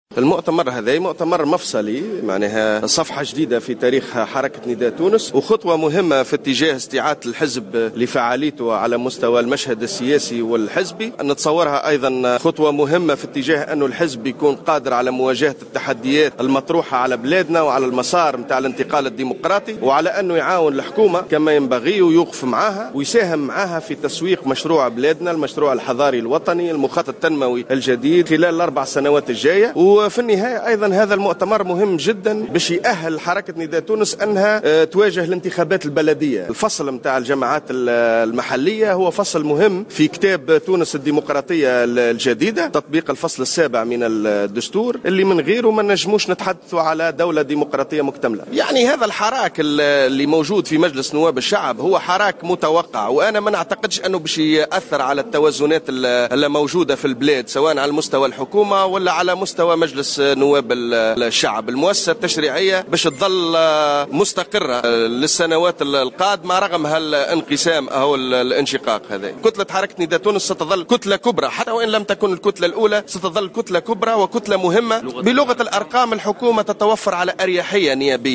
اعتبر القيادي في نداء تونس خالد شوكات الحاضر اليوم السبت 09 جانفي 2016 في المؤتمر الأول للحركة المنعقد بسوسة أن الانشقاقات في حزبه والحراك "المتوقع" داخل المجلس لن يؤثر على التوازنات داخل البرلمان أو على مستوى الحكومة وفق قوله.